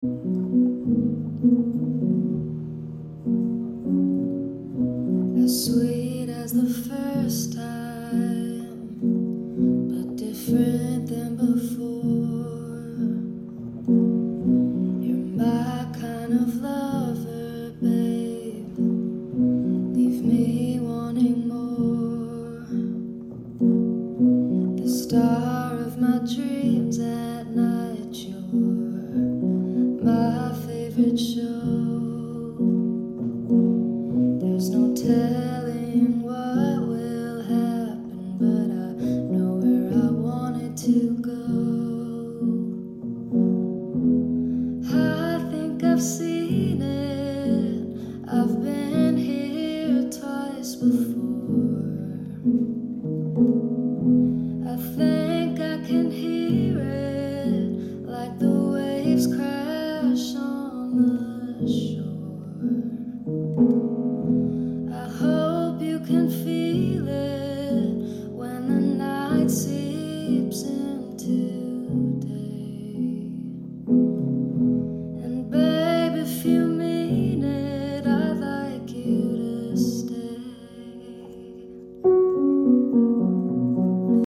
I ran out of breath at the end.